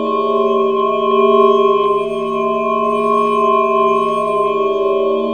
A#3 NEURO04R.wav